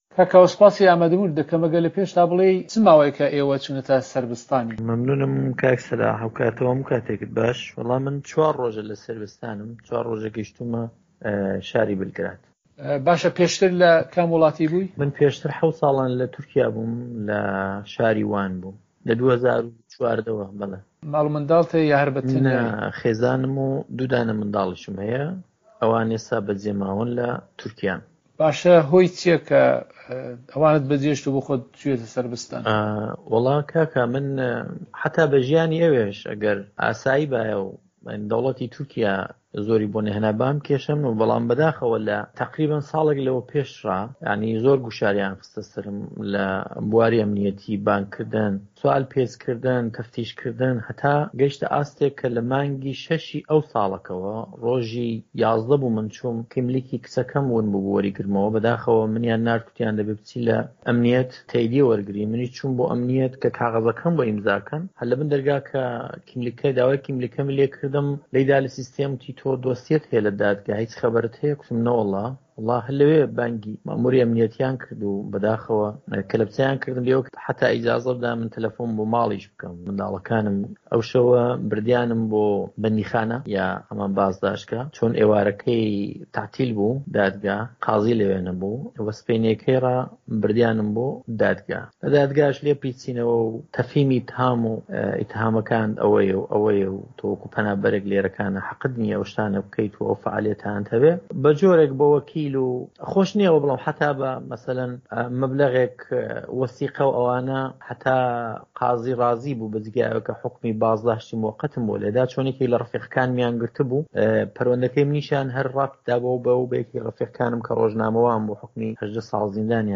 کوردێکی پەنابەری سەردەشتی پاش حەوت ساڵ لە تورکیا پەنا بۆ سەربیا دەبات و چیرۆکەکەی بۆ دەنگی ئەمەریکا دەگێڕێتەوە
وتووێژ